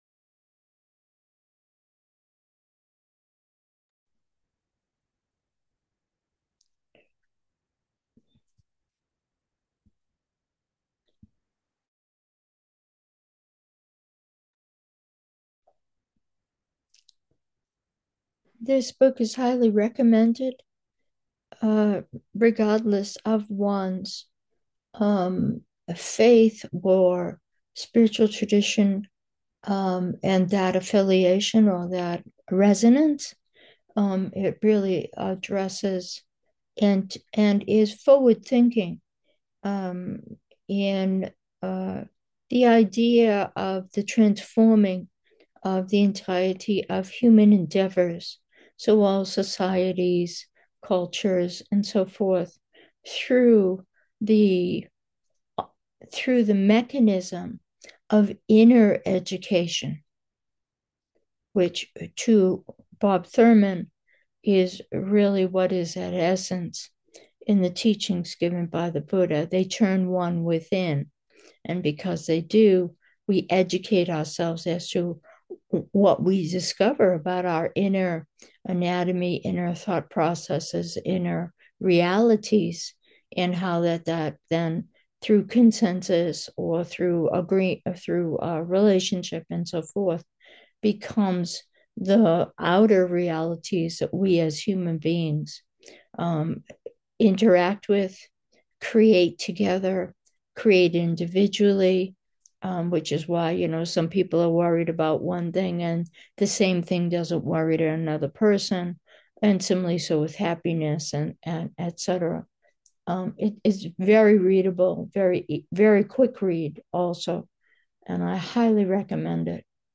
***** The first and last sentences of this quotation are taken up before a long, silent meditation.